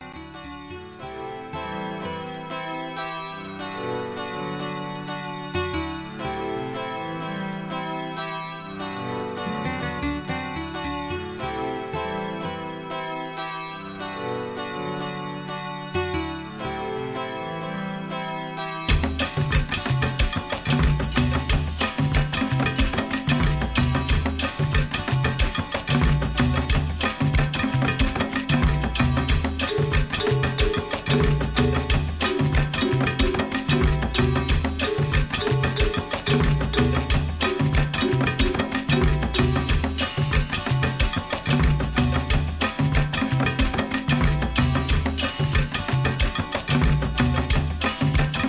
JAPANESE HIP HOP/R&B
エレクトロ、 ブレイクビーツ